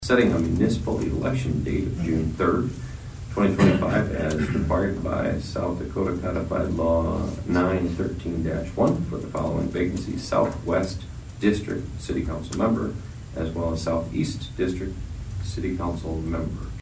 ABERDEEN, S.D.(HubCityRadio)- At Monday’s Aberdeen City Council meeting, the council released the official date for the upcoming elections for two city council seats.
Aberdeen Mayor Travis Schaunaman: